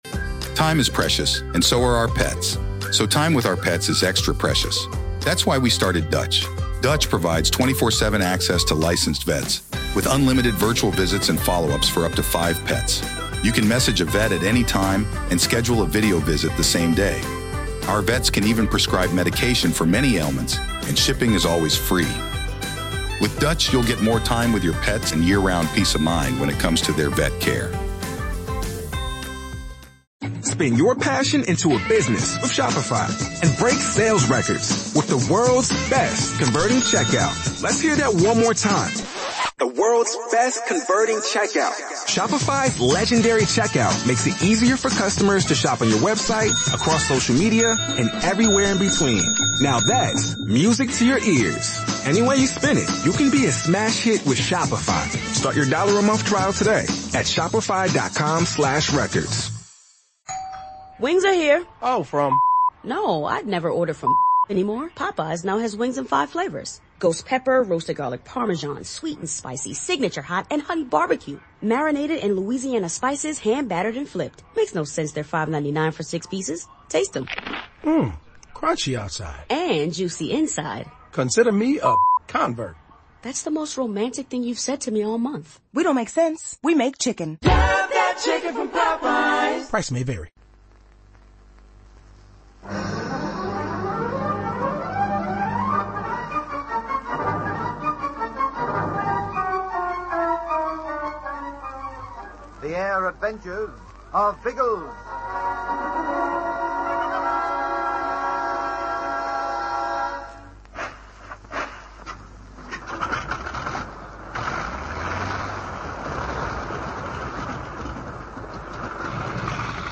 The Air Adventures of Biggles was a popular radio show that ran for almost a decade in Australia, from 1945 to 1954. It was based on the children's adventure novels of the same name by W.E. Johns, which chronicled the exploits of Major James Bigglesworth, a World War I flying ace who continued to have thrilling adventures in the years that followed.